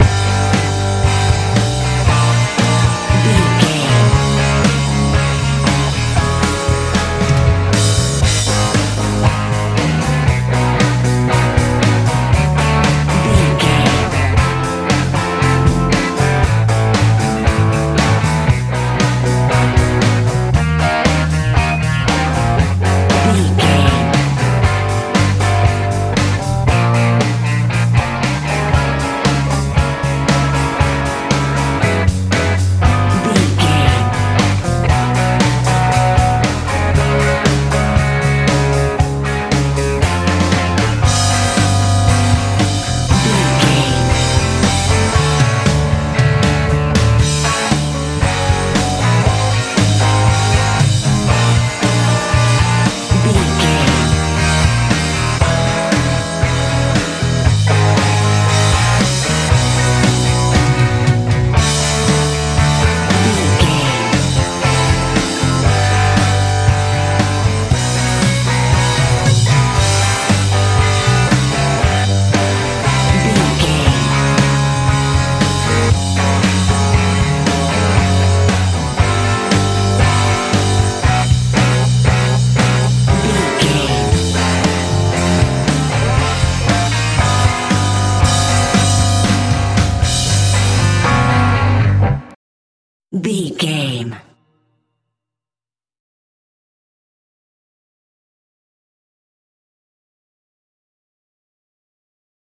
rock n roll feel
Ionian/Major
driving
energetic
electric guitar
bass guitar
drums
80s
strange